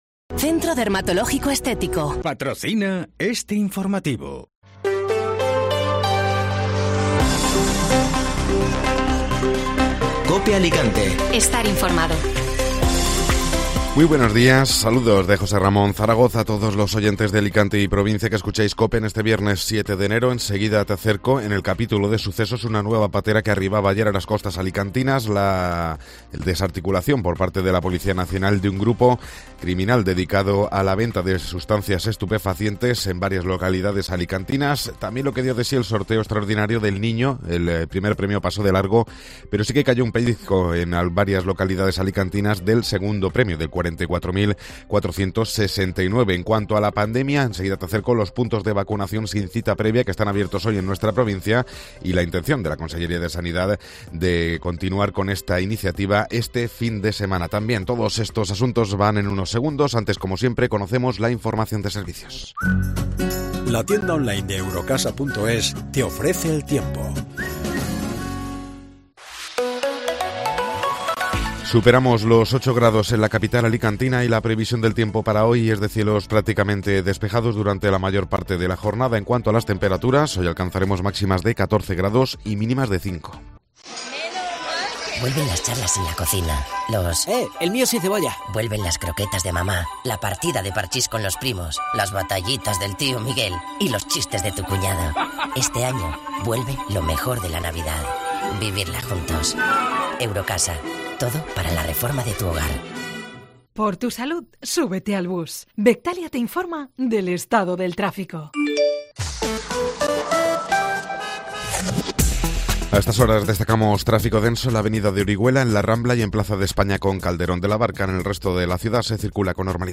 Informativo Matinal (Viernes 7 de Enero)